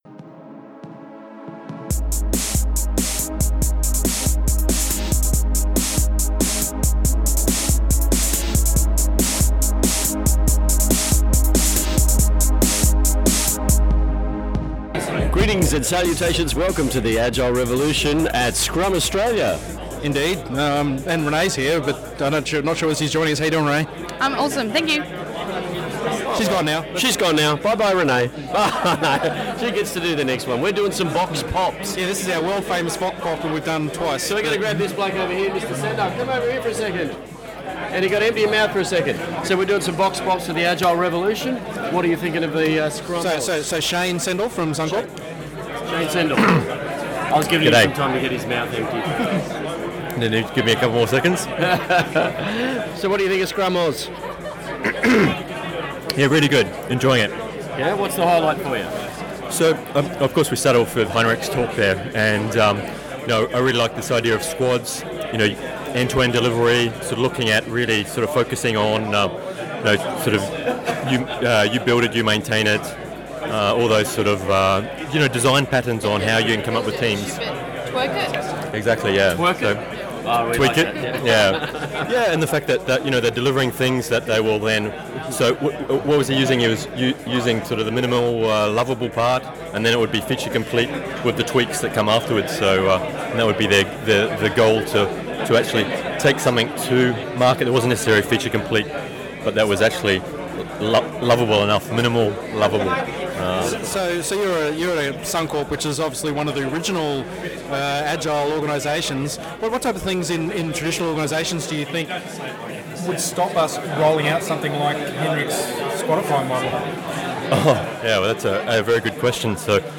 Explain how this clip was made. Episode 85: Scrum Australia 2014 Vox Pop